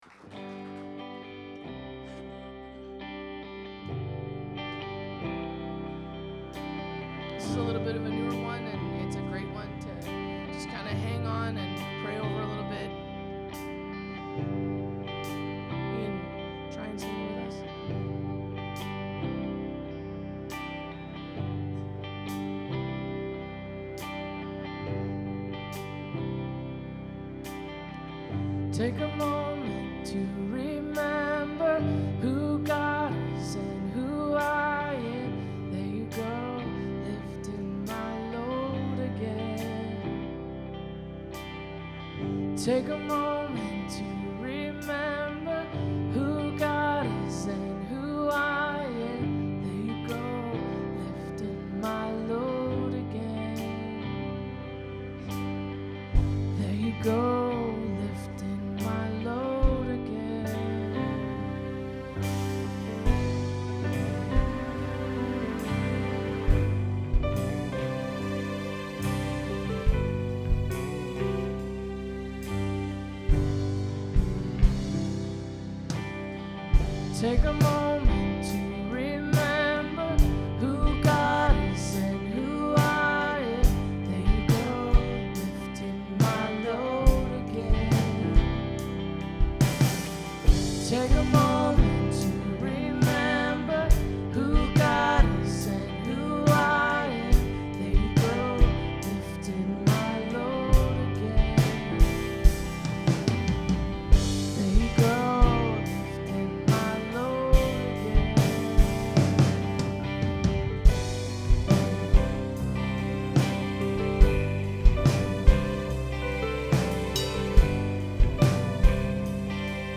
In case you missed it or would just to listen again we’ve decided to share our soundboard recordings from last night’s Engage.
Take a Moment Kingsway Worship